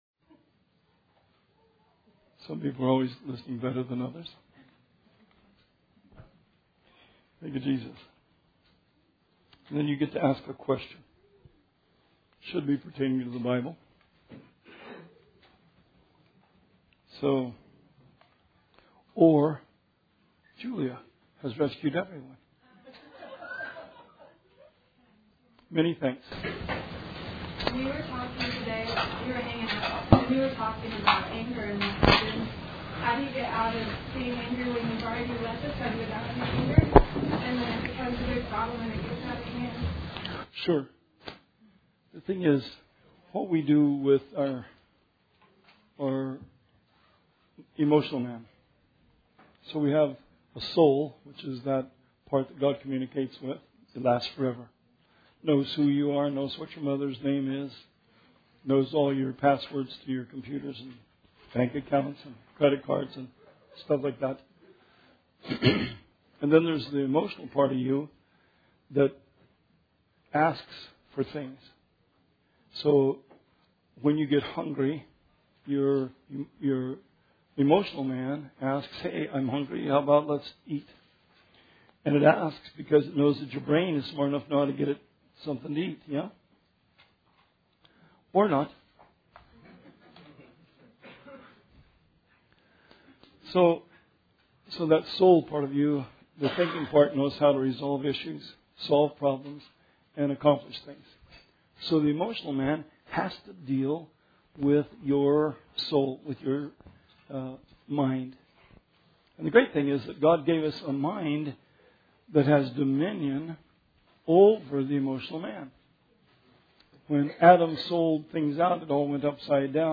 Bible Study 11/2/16